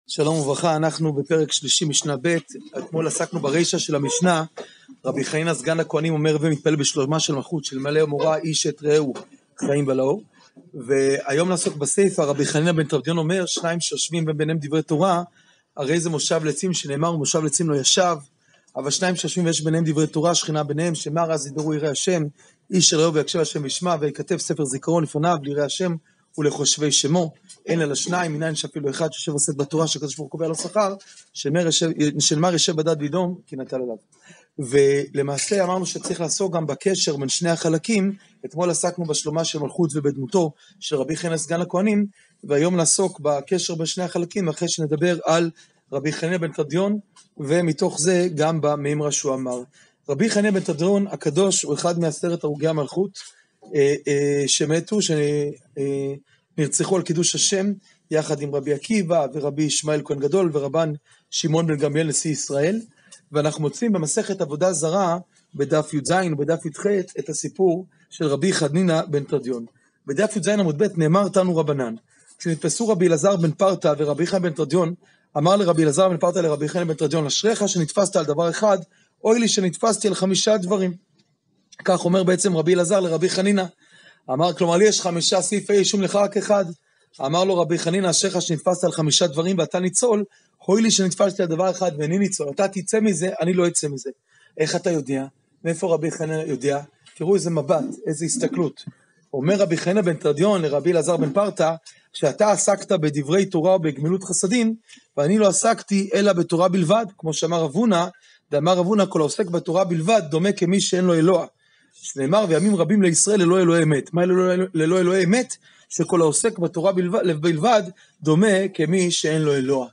שיעור פרק ג משנה ב